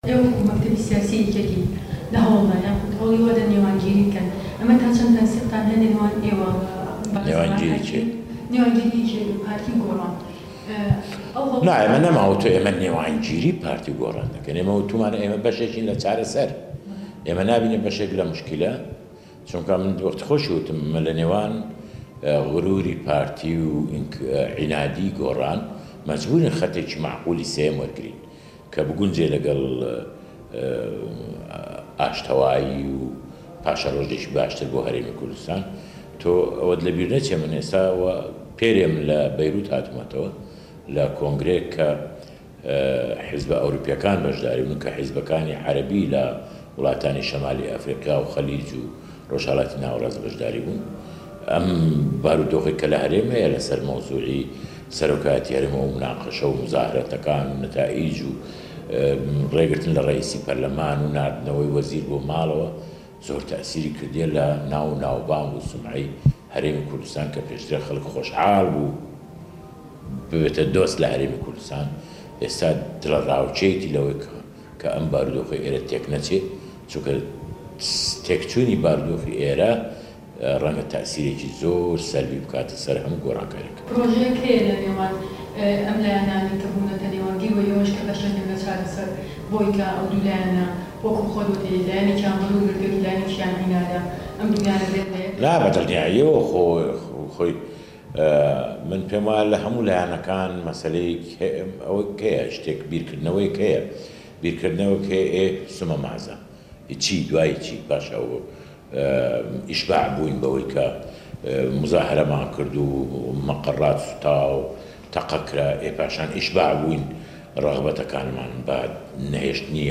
وتووێژ لەگەڵ سەعدی ئەحمەد پیرە